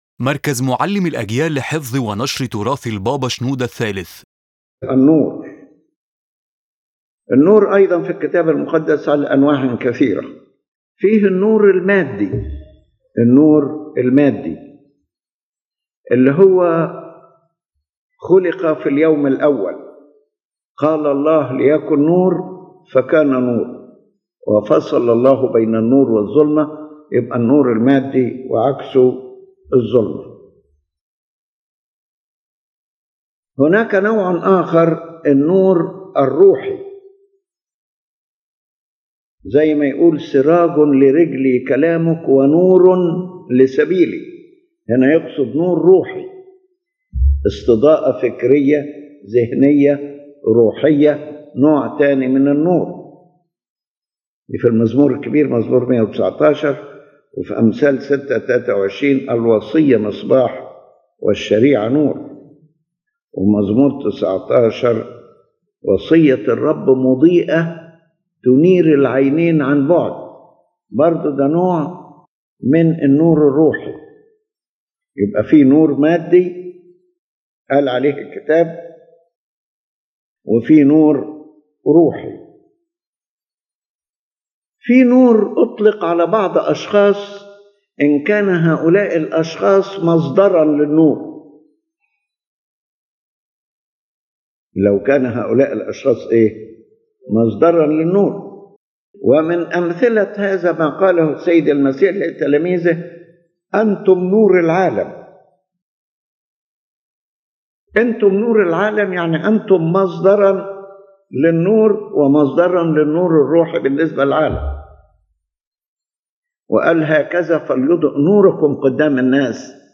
The lecturer points out that angels are described as light, and that the devil sometimes appears in the form of a “cult of light” to deceive people.